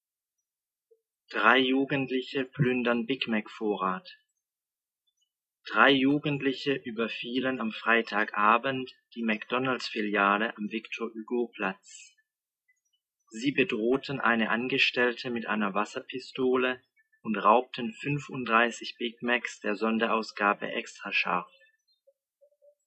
Aussprache: einen Text lesen